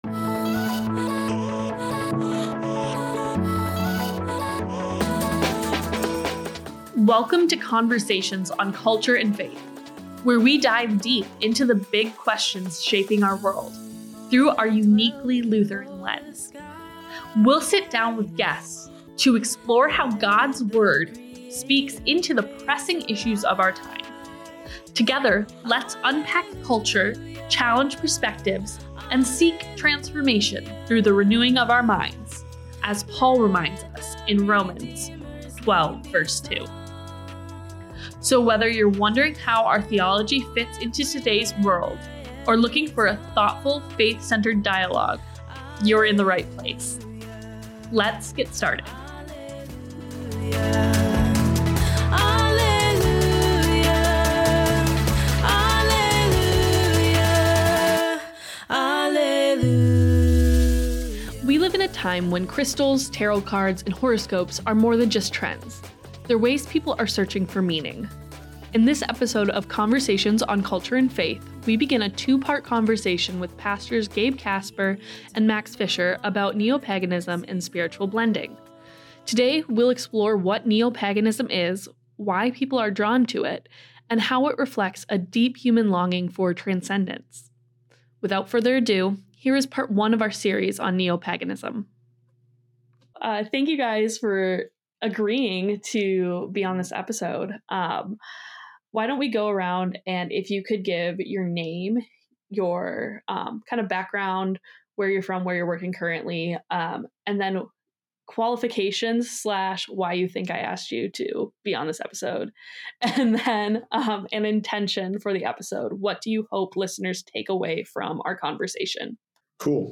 This conversation doesn’t approach Neopaganism as a scare tactic.